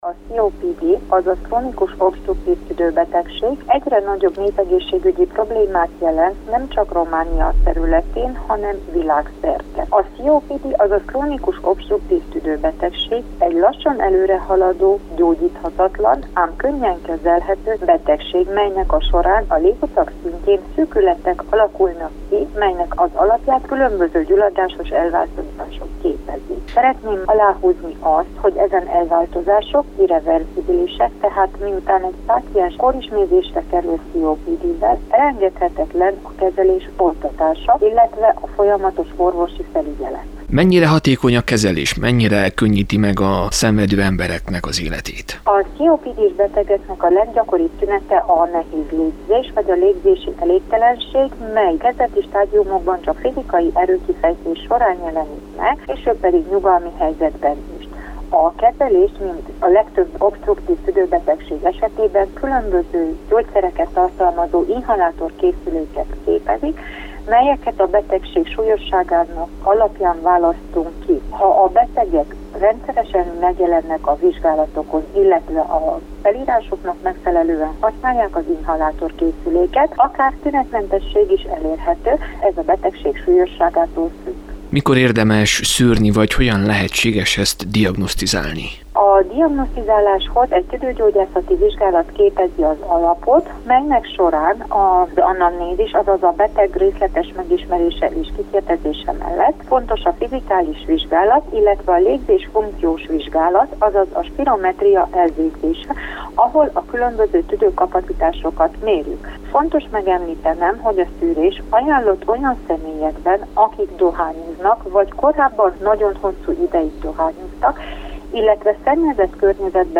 Élő Kolozsvári Rádió